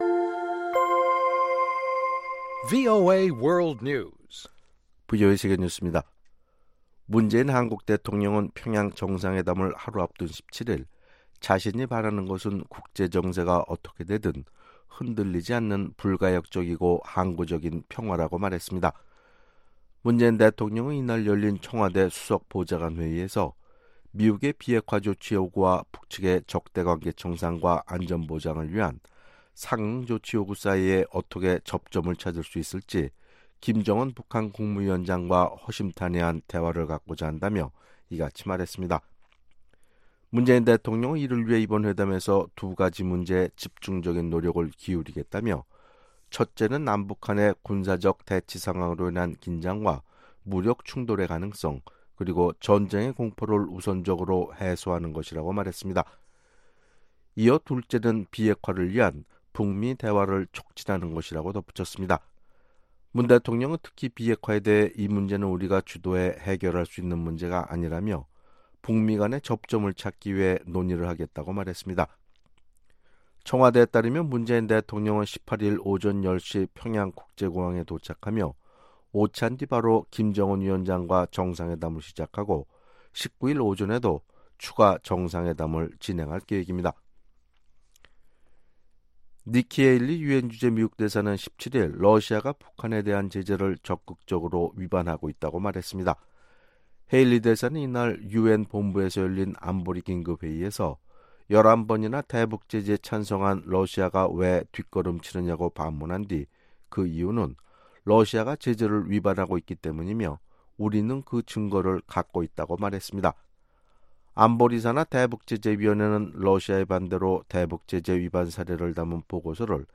VOA 한국어 아침 뉴스 프로그램 '워싱턴 뉴스 광장' 2018년 9월 18일 방송입니다. 문재인 한국 대통령의 18일 평양 방문으로 열리는 3차 남북정상회담의 중요 의제 가운데 하나는 비핵화를 위한 미-북 대화 중재와 촉진이라고 한국 청와대 대통령 비서실장이 말했습니다. 미 국무부는 문재인 한국 대통령의 평양행에 주요 대기업 총수들이 동행하는데 대해 대북 제재 이행 의무를 상기시켰습니다. 북한이 시리아 등 분쟁지역에 탱크와 탄도미사일을 판매했다고 유엔 대북제재 위원회 산하 전문가패널이 지적했습니다.